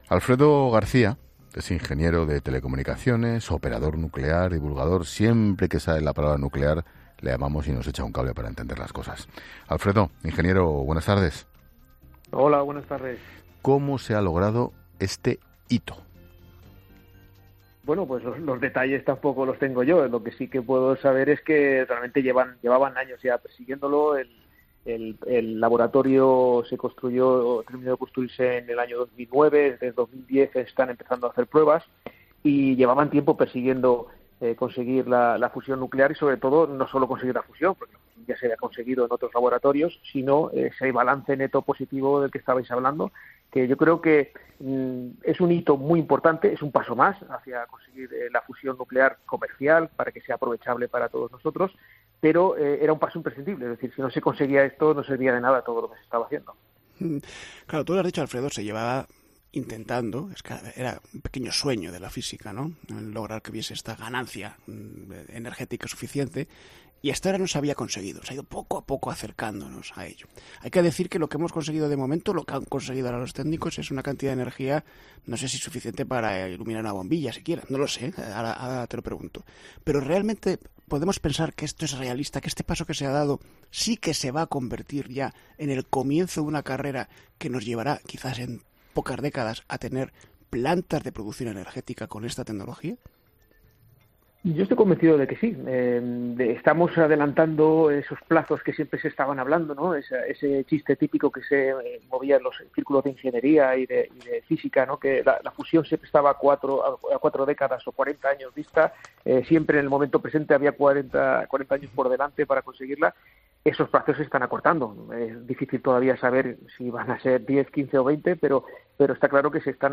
El operador nuclear ha pasado por los micrófonos de La Linterna para aclarar por qué la noticia del hallazgo en el Lawrence Livermore es histórica